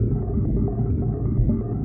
Index of /musicradar/rhythmic-inspiration-samples/130bpm